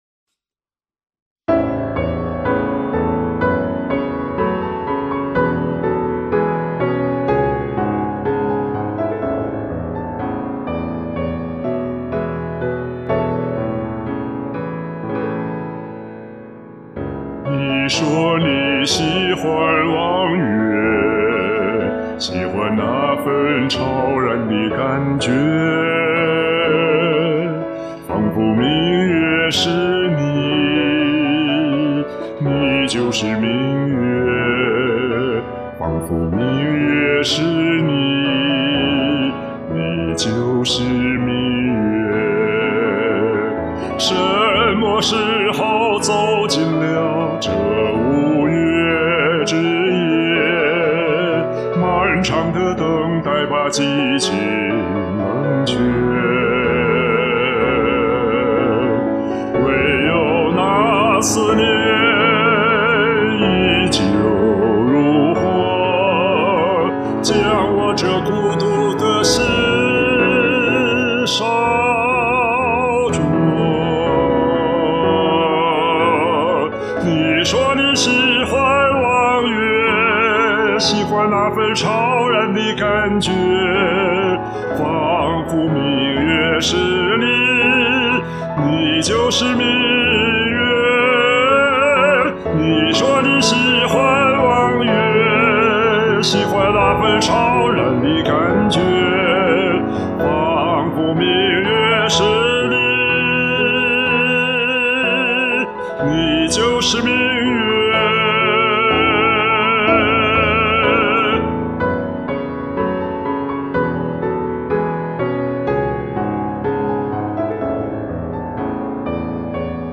鼓掌，钢琴伴奏是另一种风格，室内音乐会感觉~